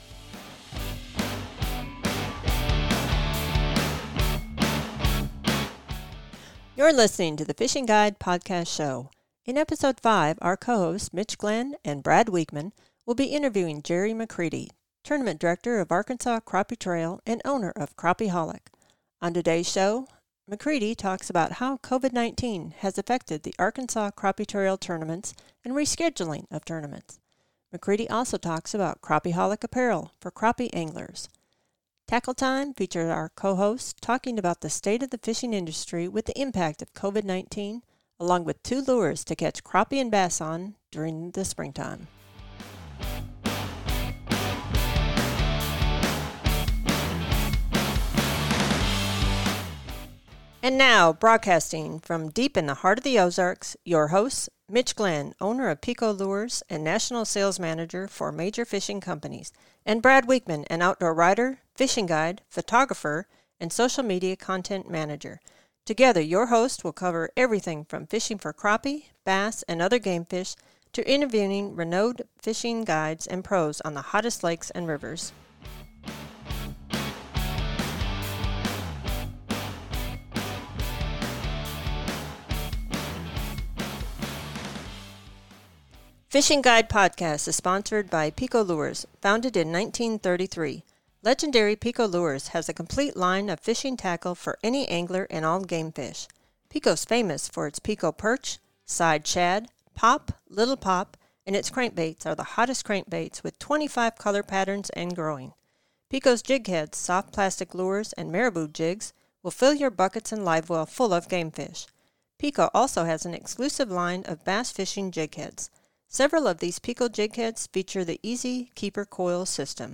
Tackle Time features our co-hosts talking about the state of the fishing industry with the impact of COVID-19 along with two lures to catch crappie and bass on during the springtime.